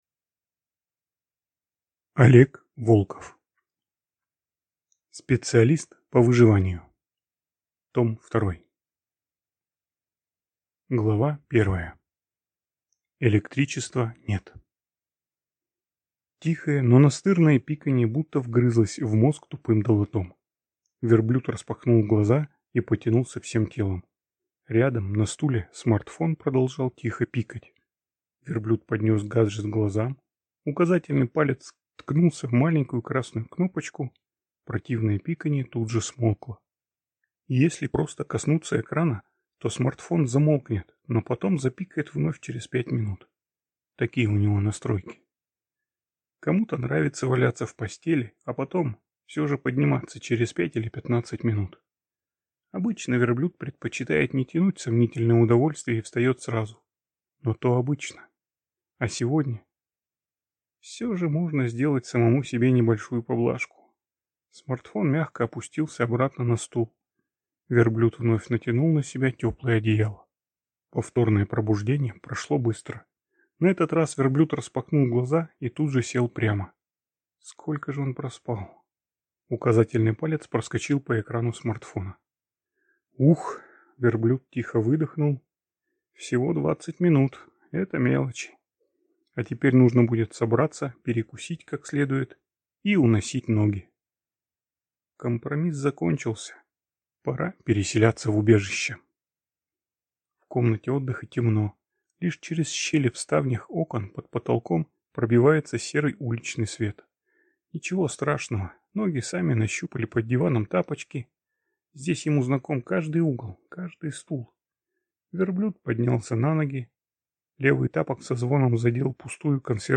Аудиокнига Специалист по выживанию. Том II | Библиотека аудиокниг